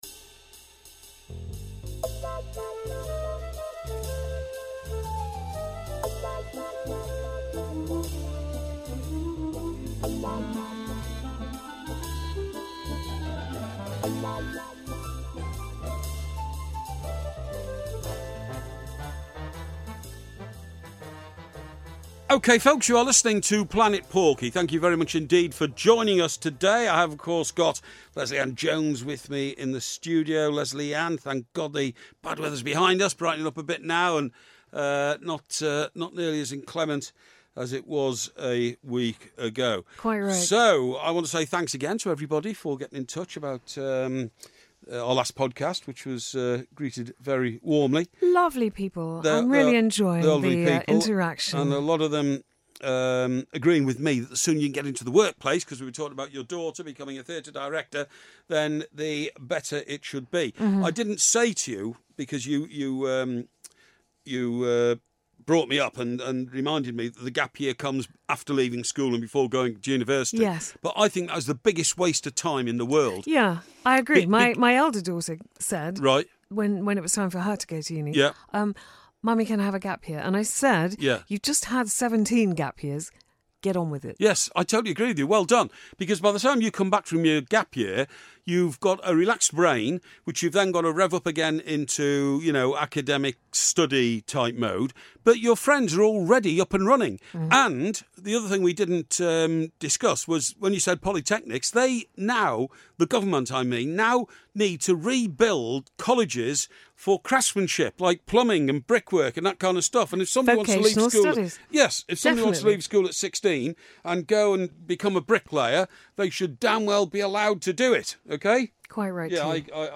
On this alcohol-soaked episode of Planet Porky, Mike Parry and Lesley-Ann Jones discuss pointless gap years, HS2, the greatest TV shows of all time, 'Whitney Houston's' new tour, Jimmy Greaves, the life of an alcoholic, drinking habits before and after work and breakfast at hotels.